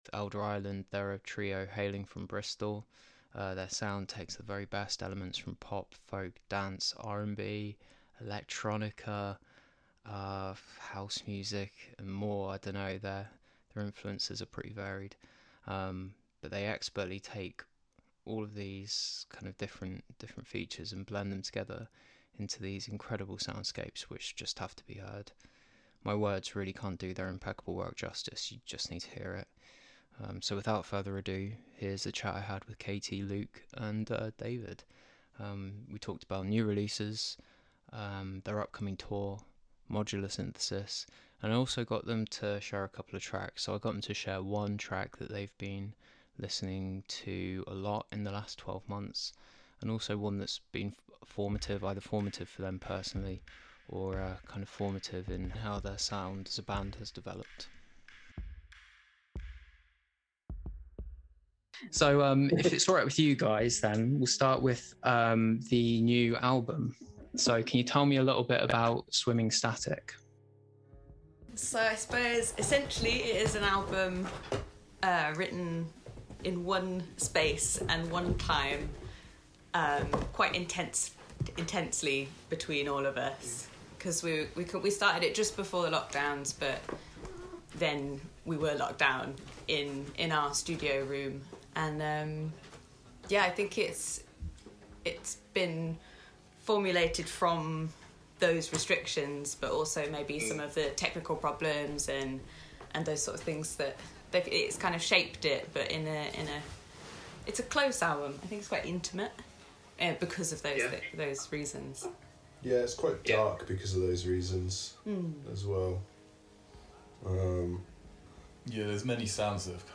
Interview with Elder Island covering their new album, upcoming tour and some of their favourite music